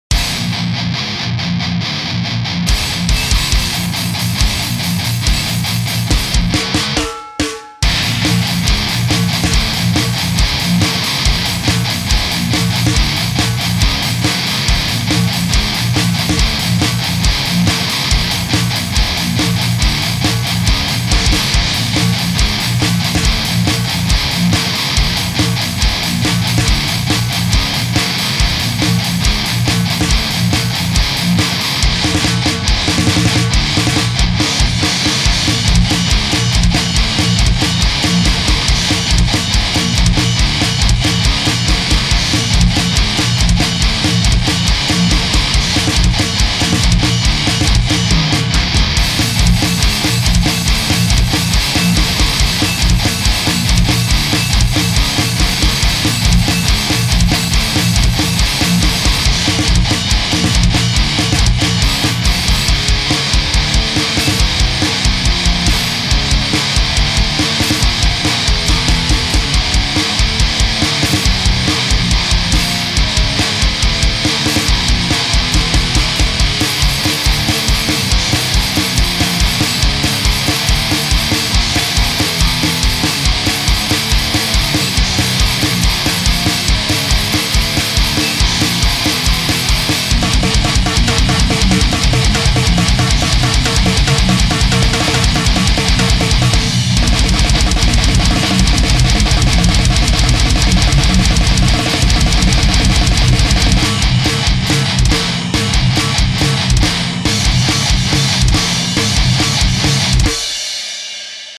Addictive drums , tse x40